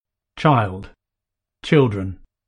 child (children) [tʃaɪld, tʃɪldrən]